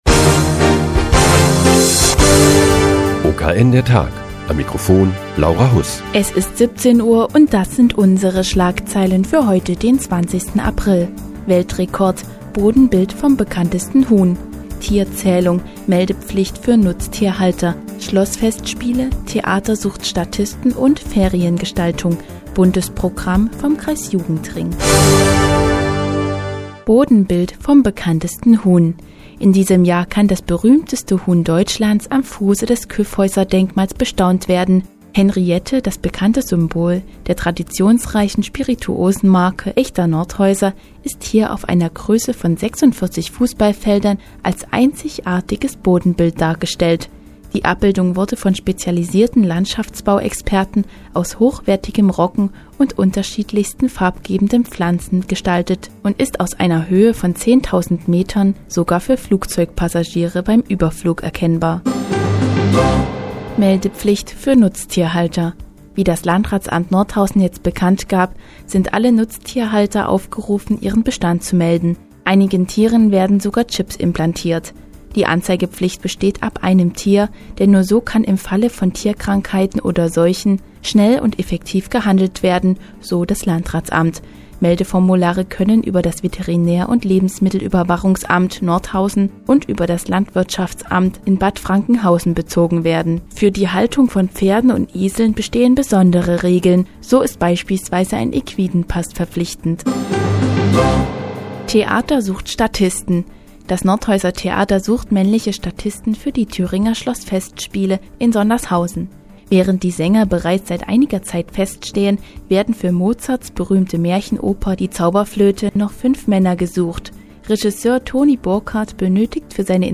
Die tägliche Nachrichtensendung des OKN ist nun auch in der nnz zu hören. Heute geht es um die Meldeplicht für Nutztierhalter und die Kinderferienfreizeit veranstaltet vom Kreisjugendring Nordhausen.